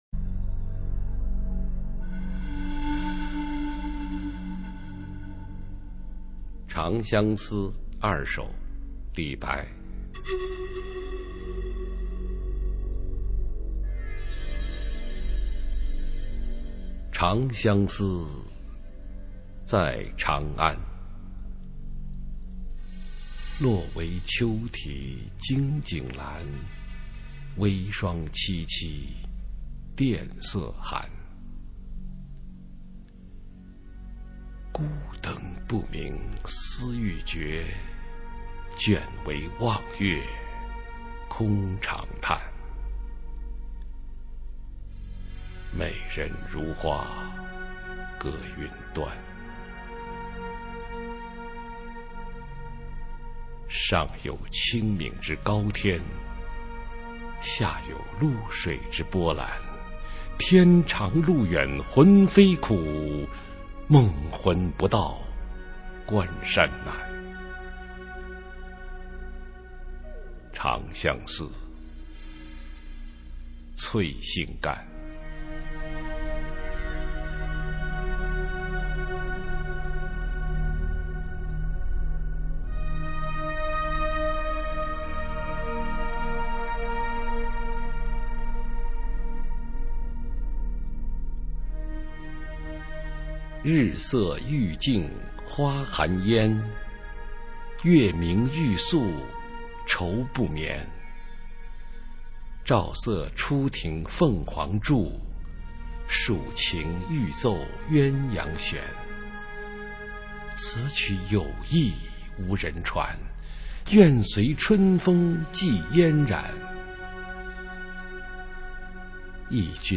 普通话美声欣赏：常相思二首　/ 佚名